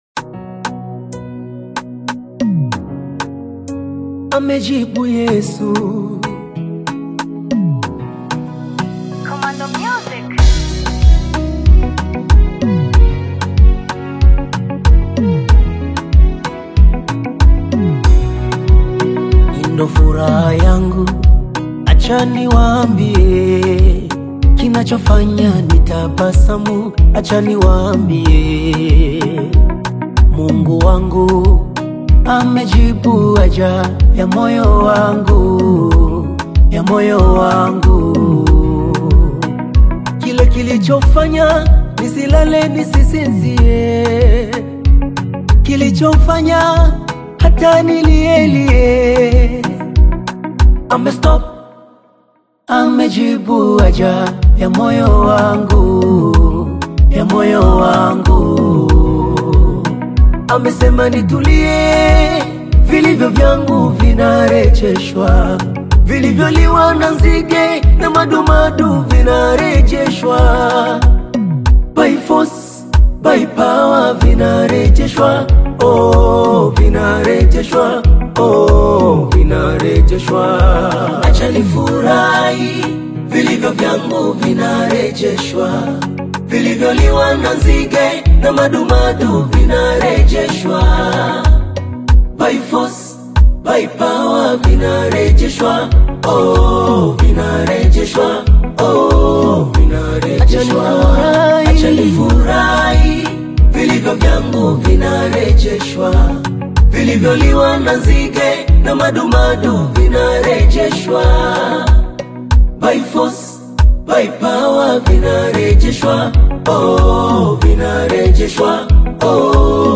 joyful Tanzanian gospel single
blends vibrant rhythms with spirited vocal delivery
feel-good gospel sound that inspires joy and faith